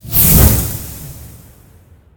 tbd-station-14/Resources/Audio/Effects/teleport_arrival.ogg
teleport_arrival.ogg